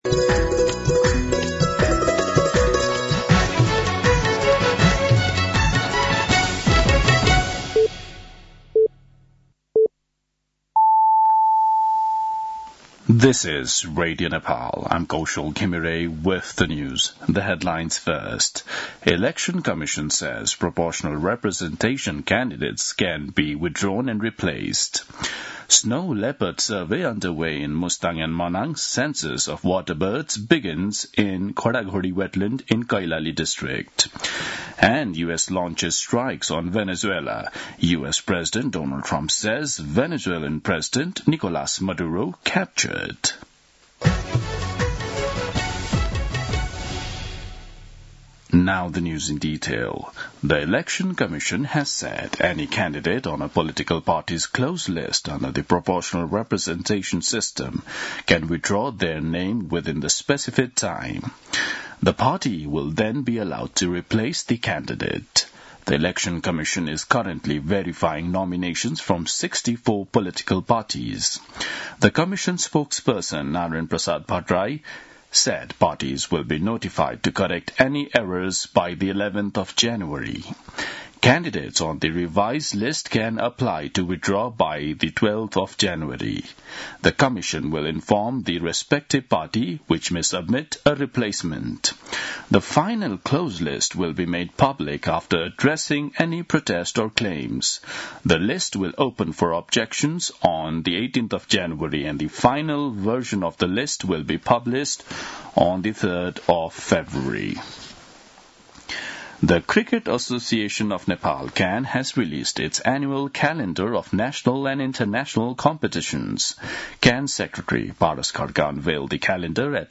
बेलुकी ८ बजेको अङ्ग्रेजी समाचार : १९ पुष , २०८२